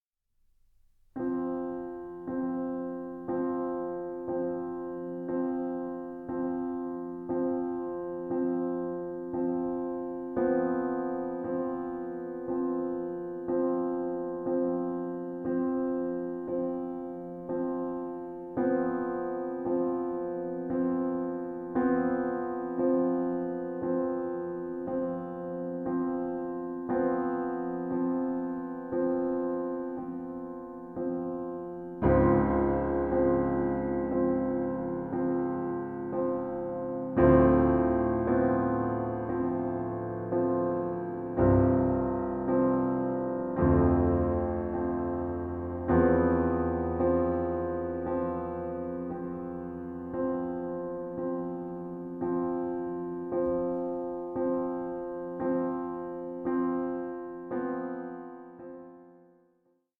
organist and keyboard player